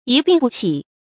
一病不起 注音： ㄧ ㄅㄧㄥˋ ㄅㄨˋ ㄑㄧˇ 讀音讀法： 意思解釋： 得病后病情一天天惡化，終至死亡。